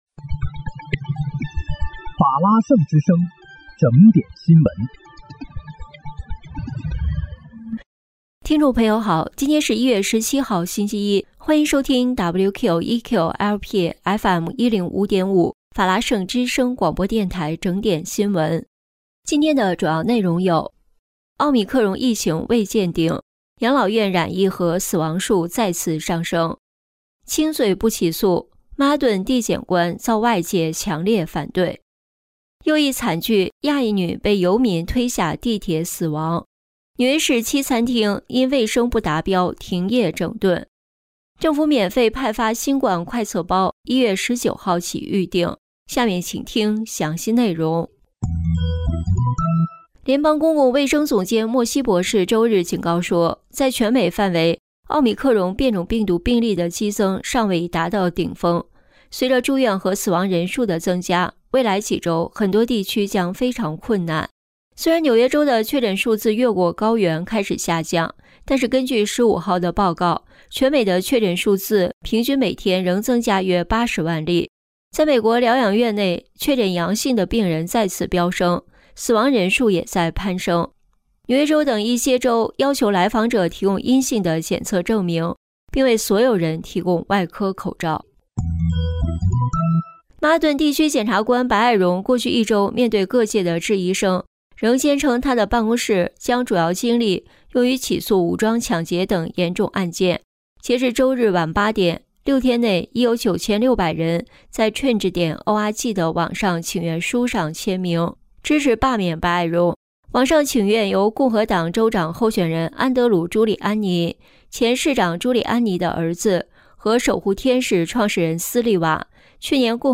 1月17日（星期一）纽约整点新闻